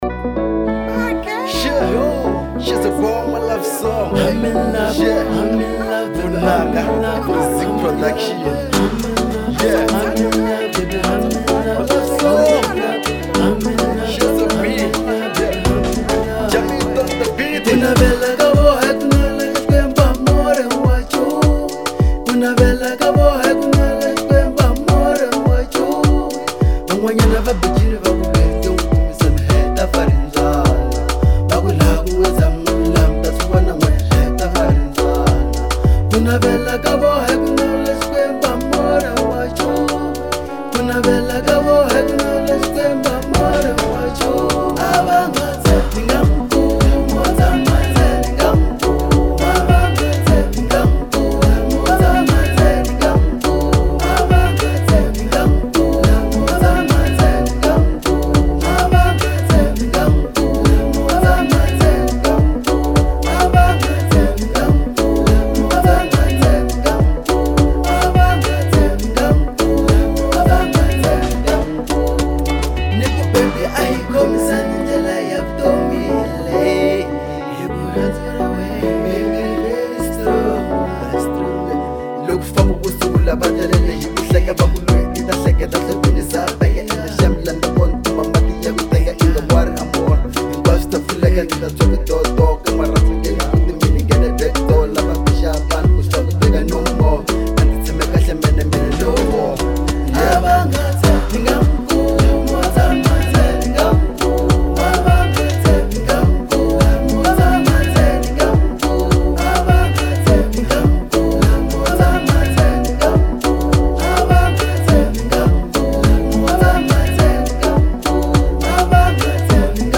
02:49 Genre : Afro Pop Size